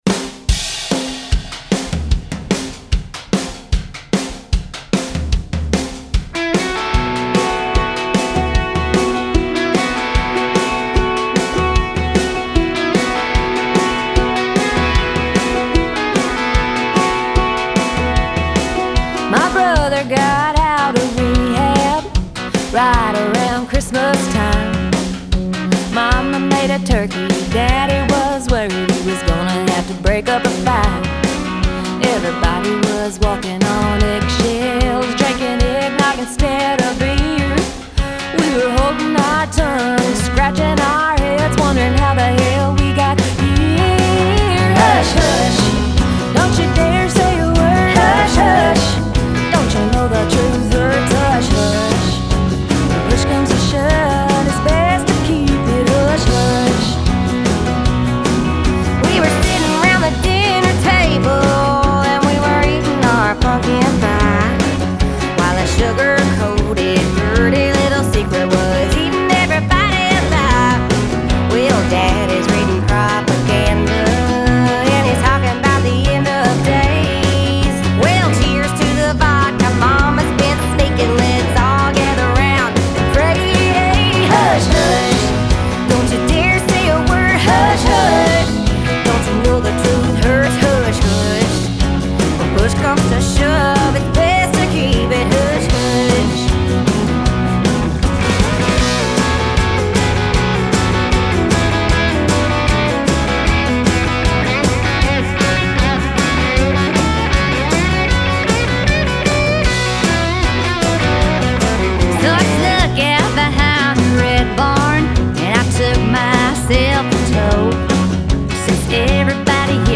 Good fun Country music.